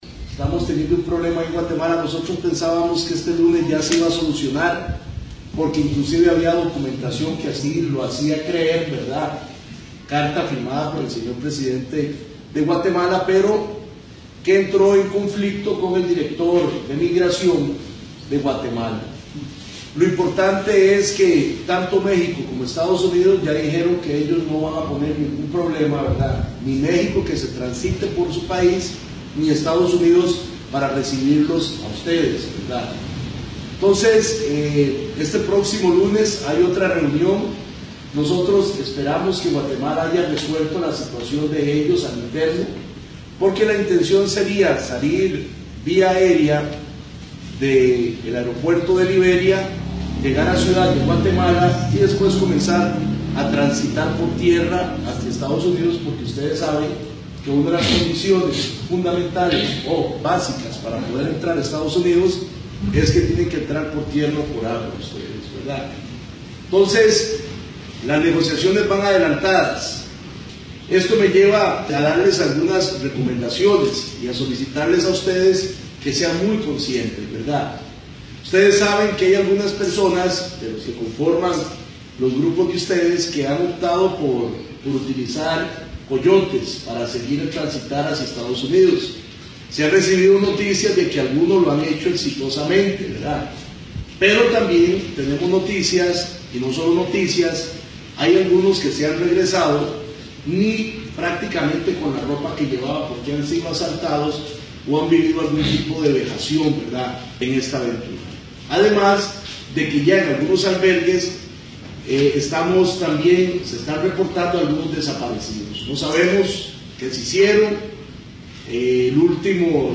MInistro de Pesca costarricense Meneses habla con cubanos albergados